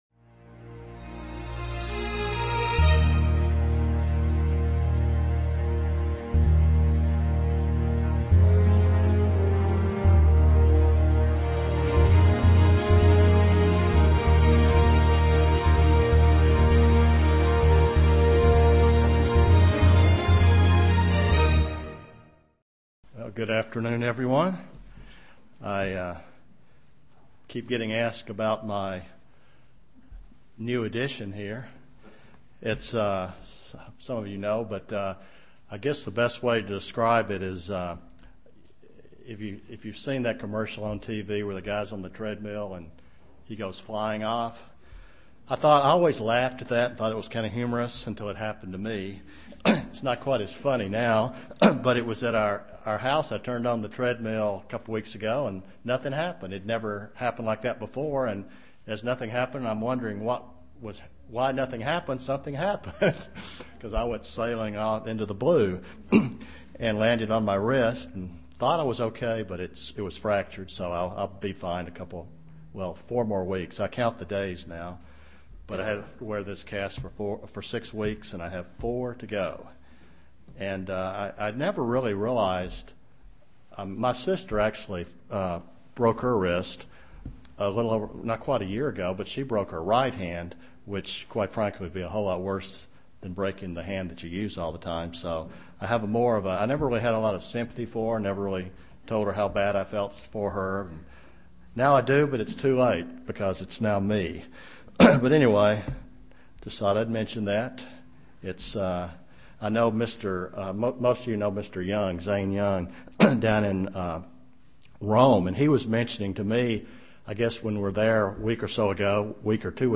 UCG Sermon Studying the bible?
Given in Chattanooga, TN